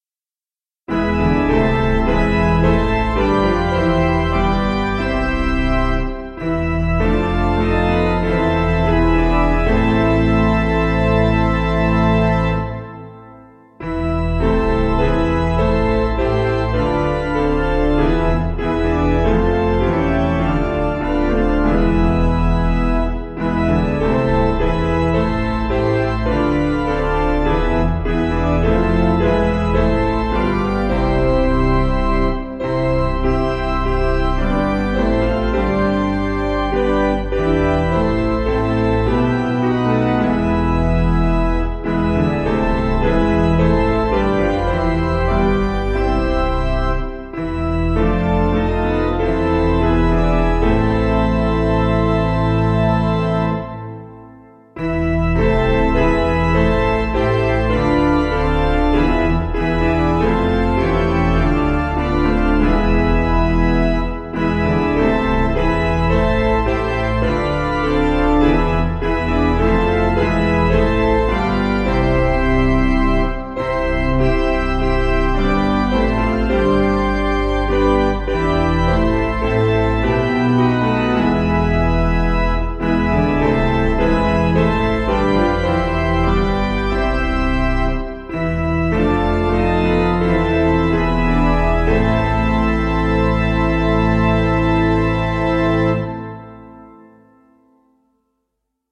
Basic Piano & Organ
(CM)   2/Am